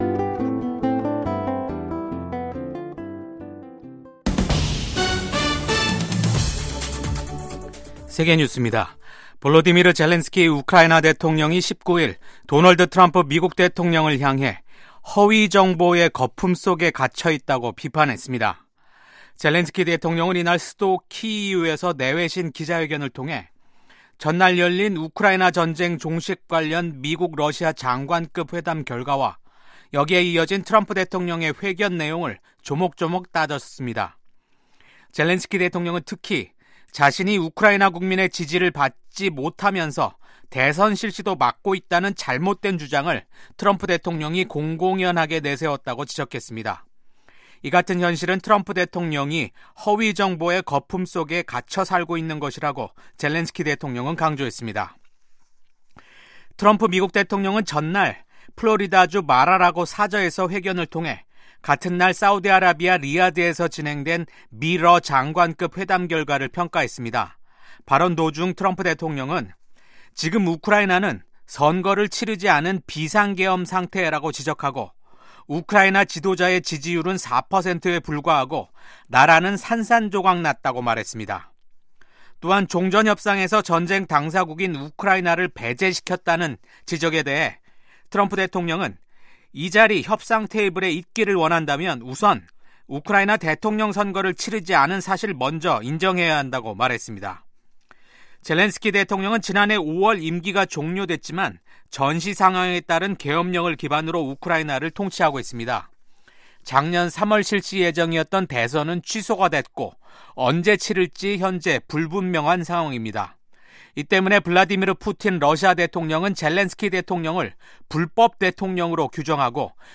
VOA 한국어 아침 뉴스 프로그램 '워싱턴 뉴스 광장'입니다. 도널드 트럼프 미국 대통령이 처음으로 러시아에 파병된 북한군의 전사 사실을 처음으로 언급한 가운데, 지난달 우크라이나 군이 생포한 북한 군 1명이 한국으로의 망명 의사를 밝혔습니다.